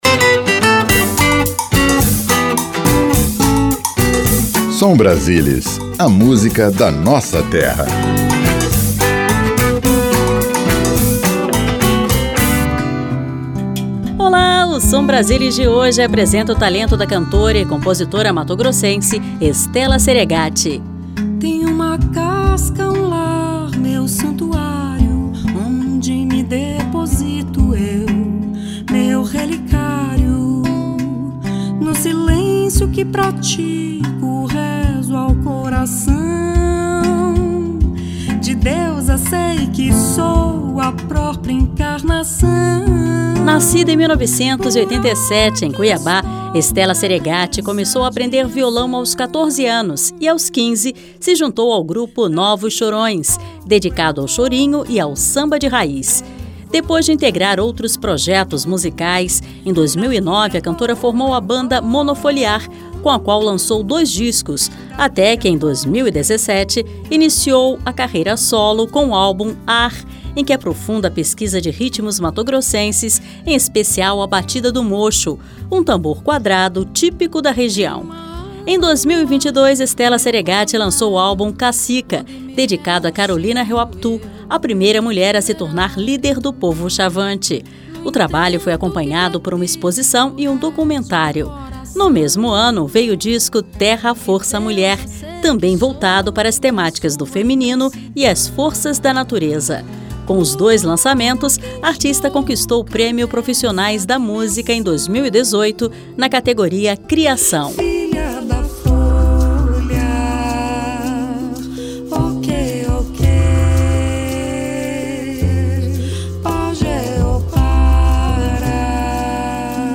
A cantora e compositora
Música Brasileira
Samba
Ao vivo